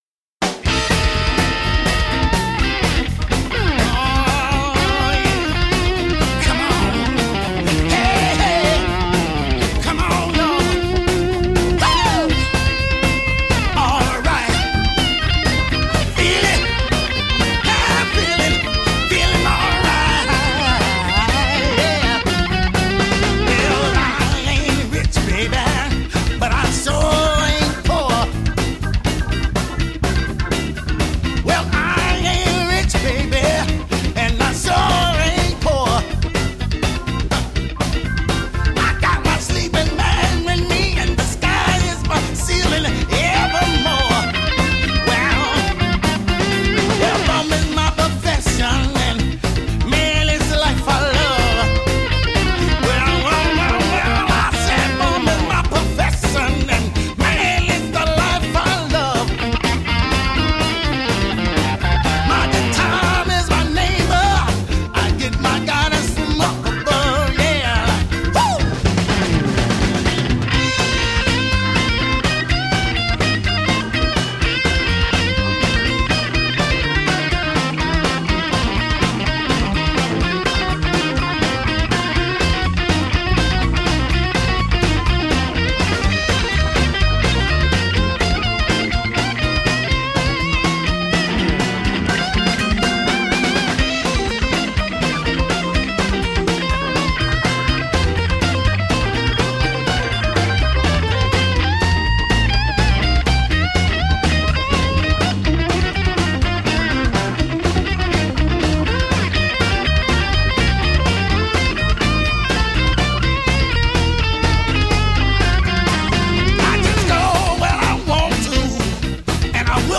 Blues guitar legend in the making.